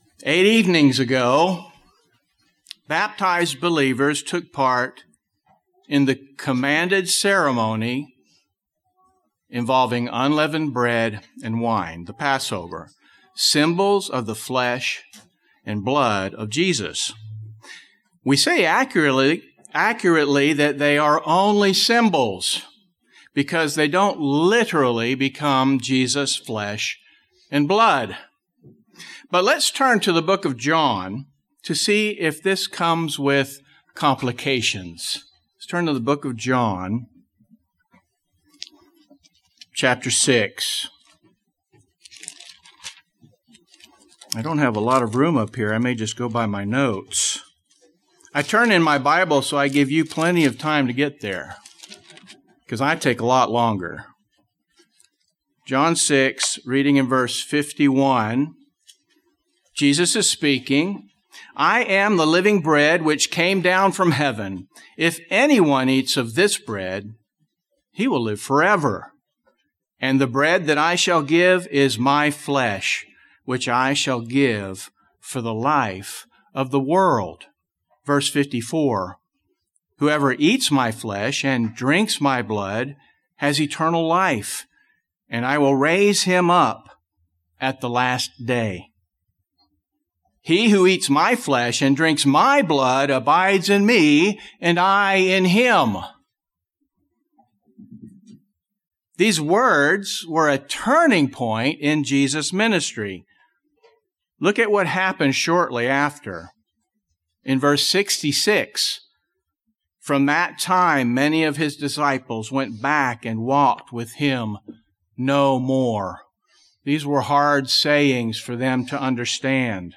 A Feast of Unleavened Bread message, combined as sermonette and offertory message...The purpose of God's Passover and His Holy Festivals is not to help faithful Christians get right with Him.